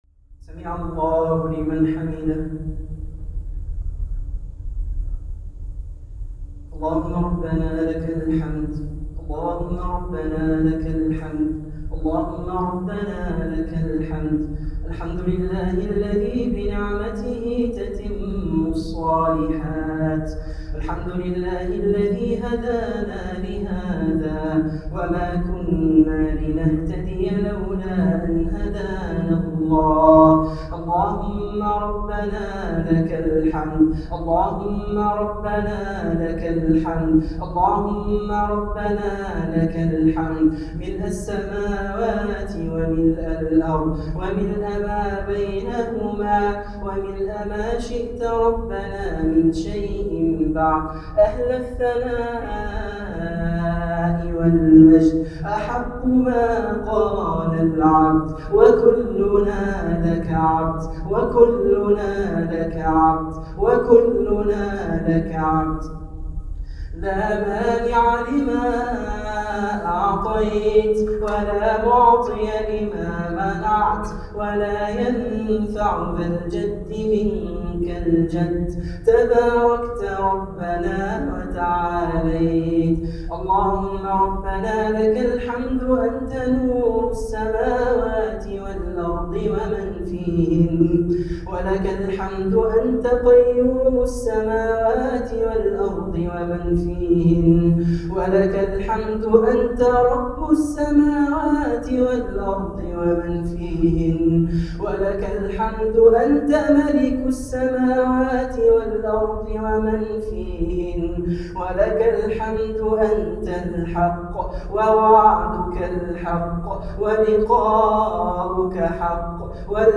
دعاء ختم القرآن 1438